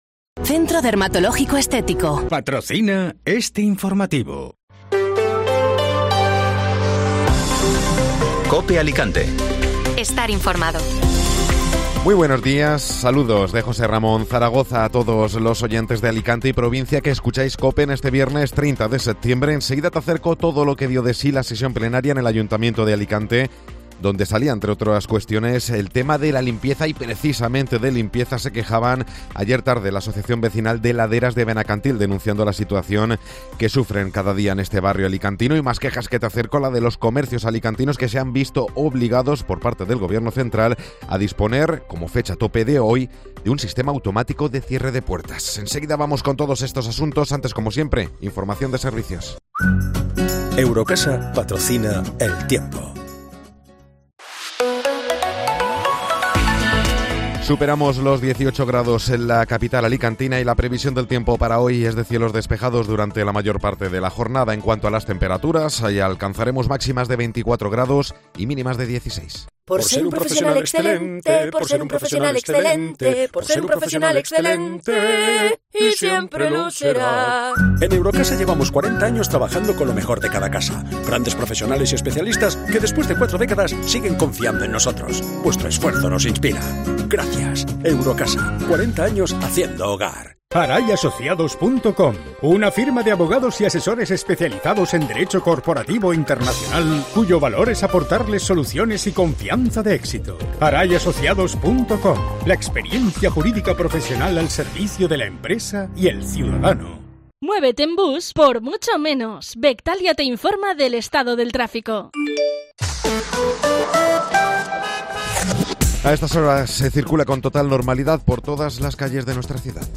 Informativo Matinal (Viernes 30 Septiembre)